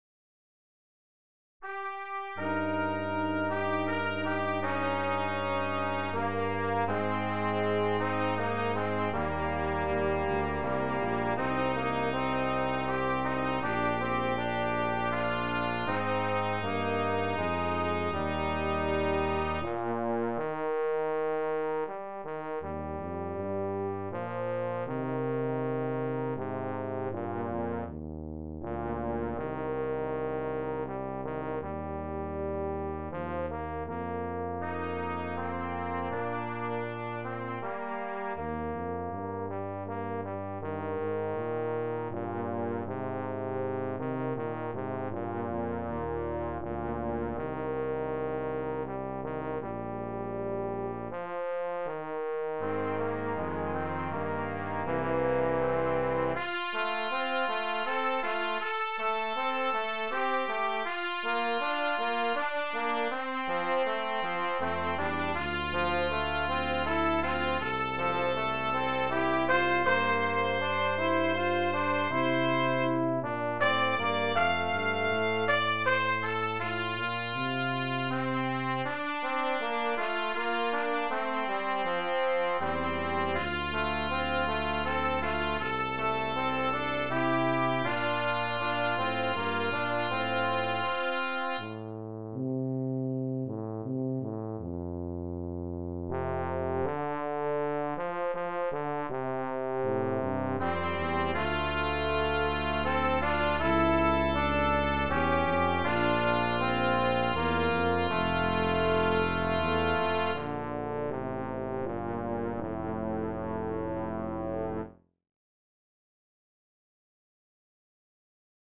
Brass Trio TTT
Traditional American melody